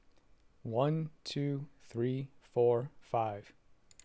Hit record and count in a clear voice: “1, 2, 3, 4, 5” then Stop.
It’s better than it was before (I found a very specific angle) but it’s still pretty quiet and hits only about a third of the max bars on the system preferences sound meter. Here’s the file - is that all that can be expected (at least, background noise is low!) or is there a way to make it louder?
You seem to have a fairly quiet voice, so if you go with a USB mic, you really need one that has a gain control to give the signal a bit of boost.